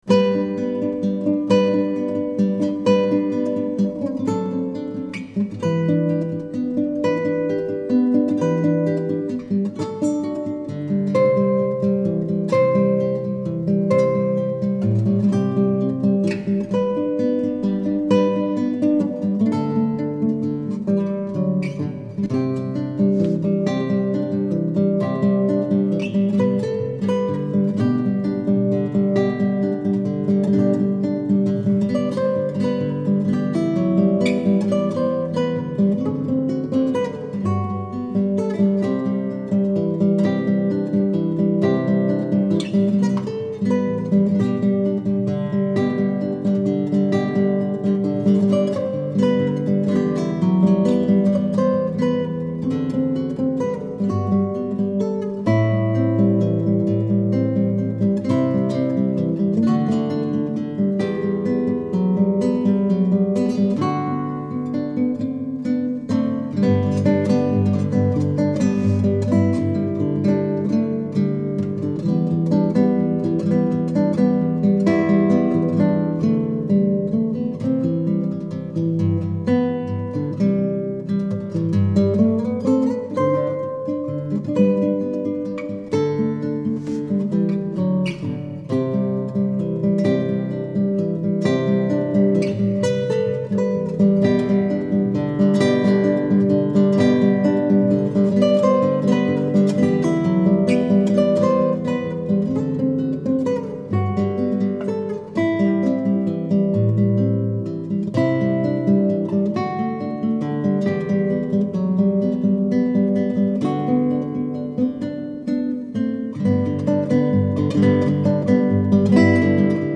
SOLOS